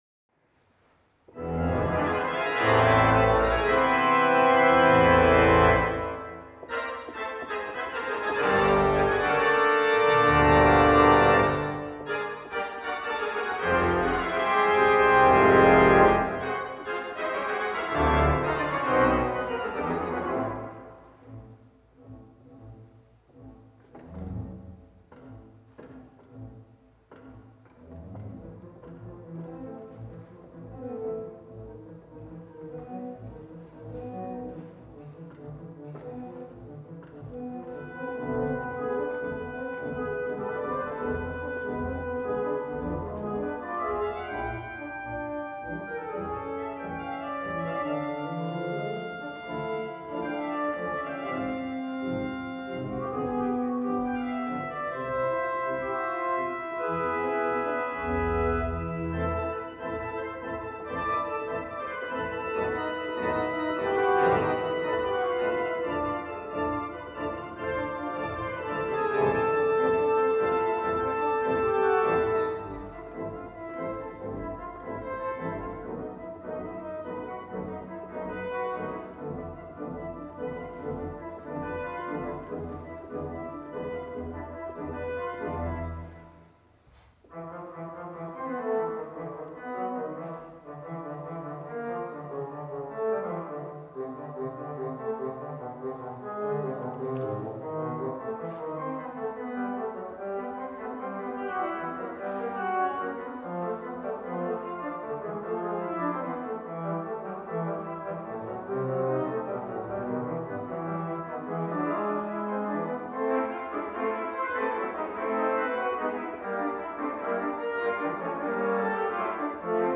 virtuoze dans
met 20 kanalen audio en een korte toegevoegde galm.